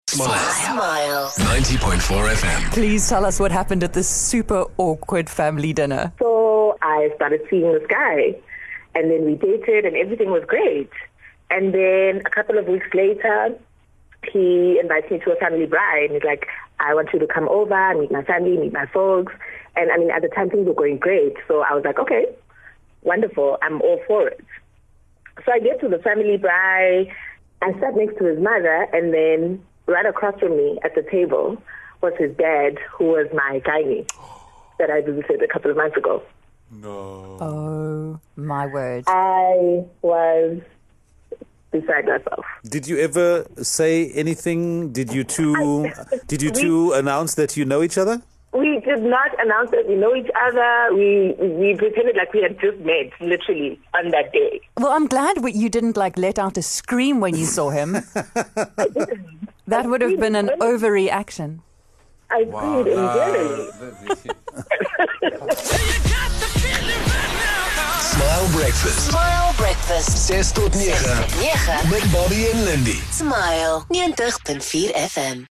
We weren't ready for a caller who told us about some family drama that had to be kept secret for years.